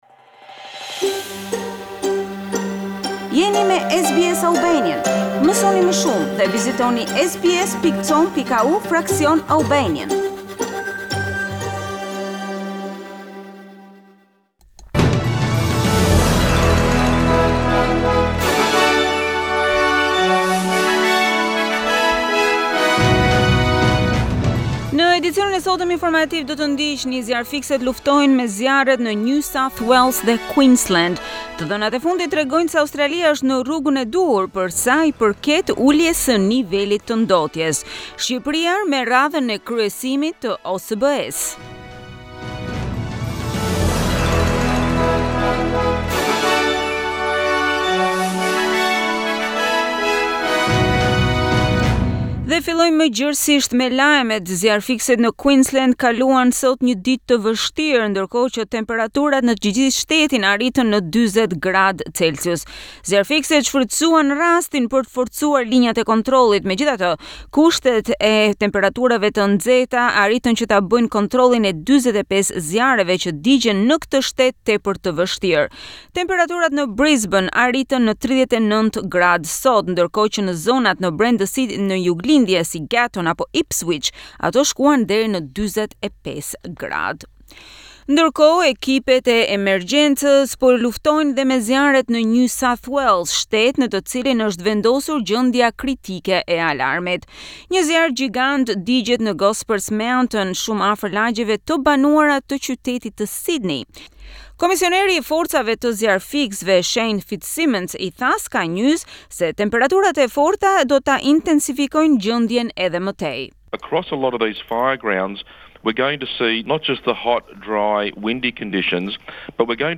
News Bulletin - 7 December 2019